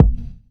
gfdd_snr.wav